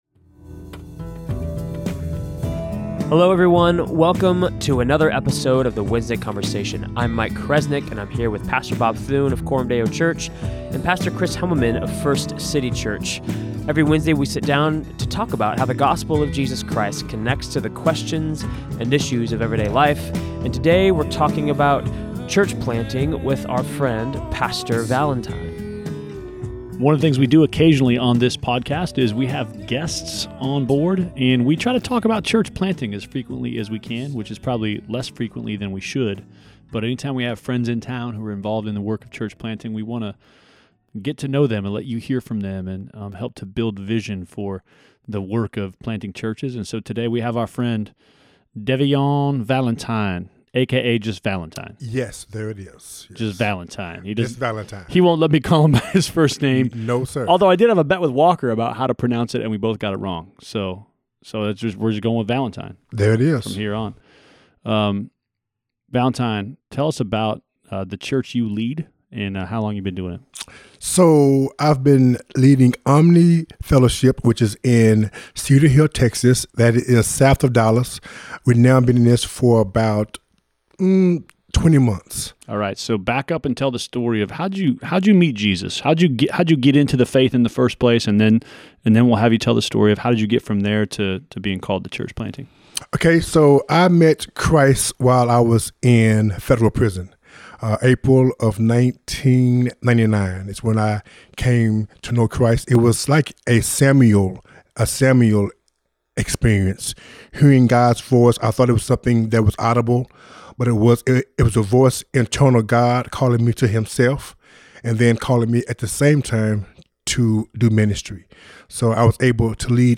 Church Planter Interview